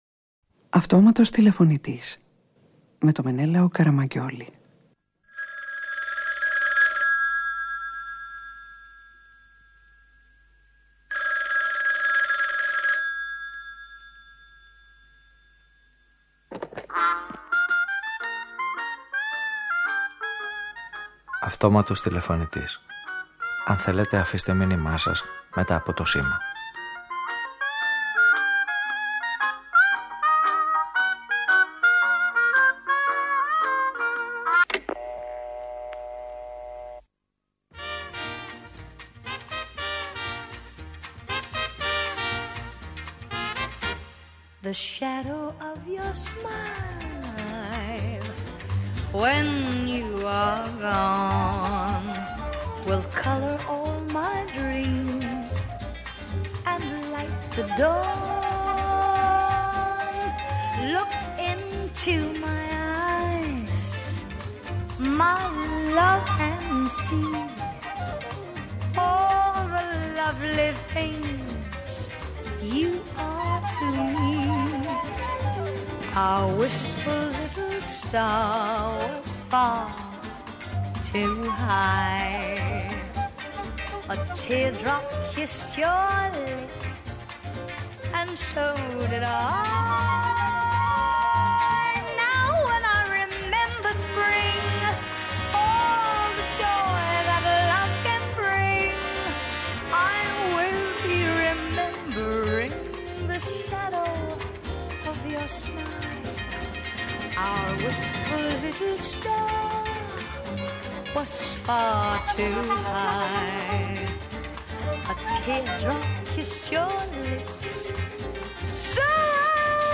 Ένα μήνυμα που ψάχνει να βρει τον τηλεφωνητή όποιου έχασε (ή παράτησε) τον καινούργιο αδέσποτο σκύλο του δρόμου μας σχηματίζει μια ραδιοφωνική ταινία γεμάτη προβοκατόρικες και ανατρεπτικές παραινέσεις για όσους νιώθουν αδέσποτοι, ανέστιοι και χαμένοι σε ένα κόσμο που επαληθεύει κάθε μέρα τους πιο τρομερούς κι απίστευτους εφιάλτες τους.